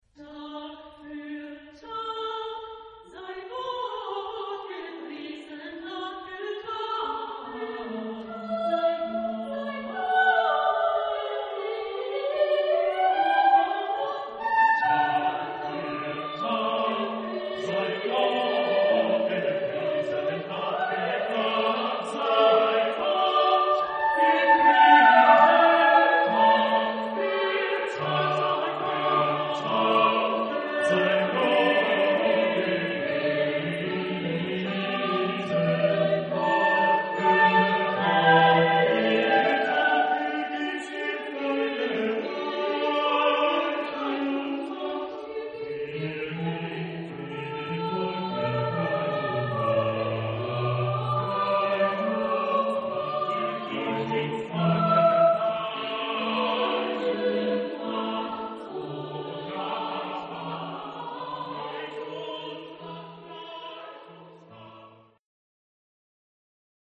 Genre-Style-Forme : Sacré ; Motet ; Psaume
Caractère de la pièce : polyphonique
Type de choeur : SSATB  (5 voix mixtes )
Tonalité : ré majeur